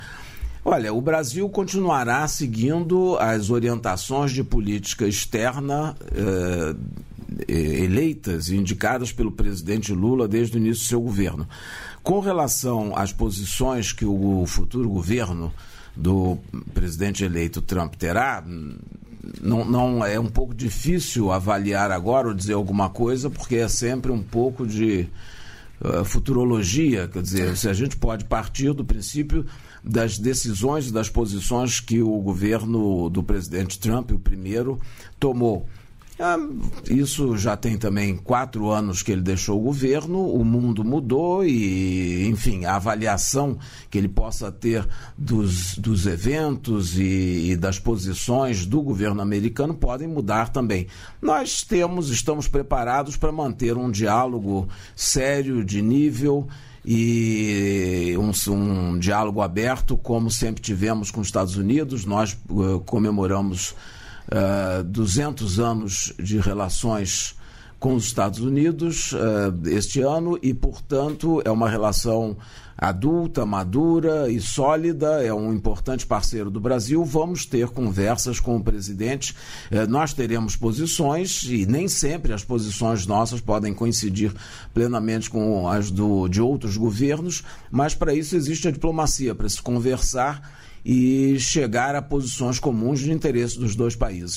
Trecho da participação do ministro das Relações Exteriores, Mauro Vieira, no programa Bom Dia, Ministro desta quarta-feira (27), nos estúdios da EBC, em Brasília.